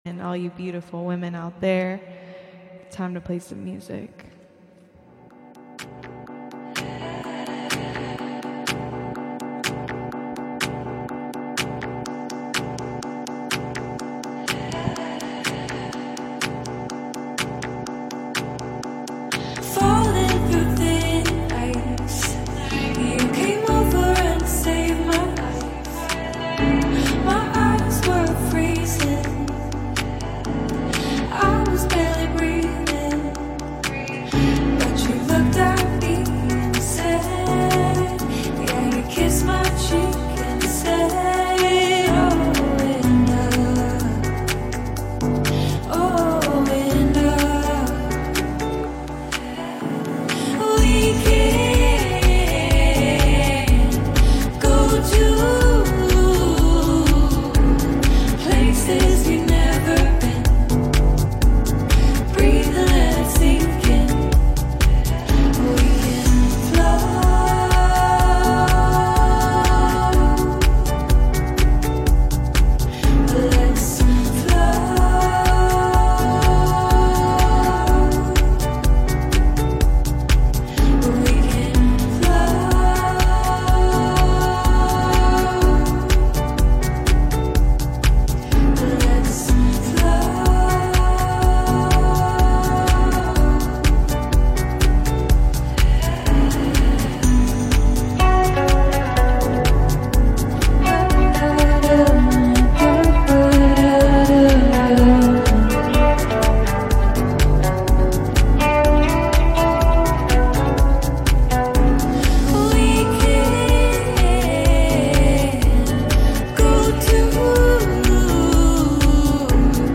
DJ Mixes and Radio